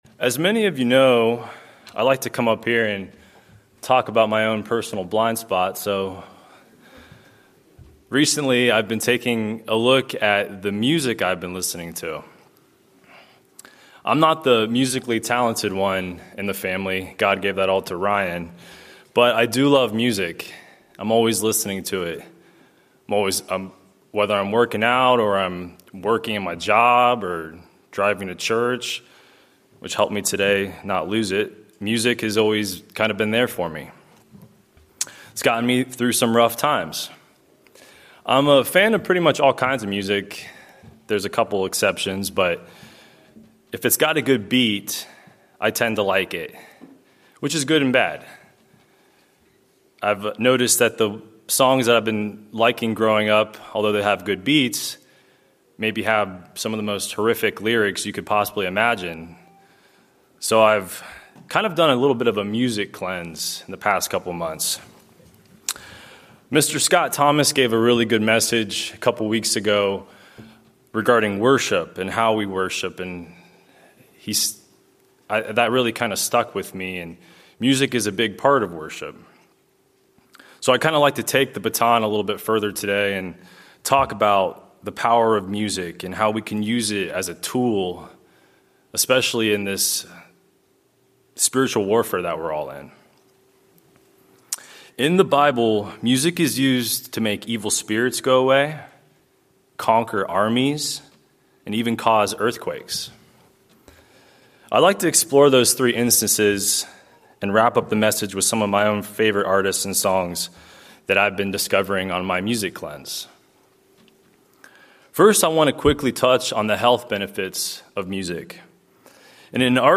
Sermons
Given in Atlanta, GA